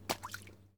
Adjust walking sounds
blood2.ogg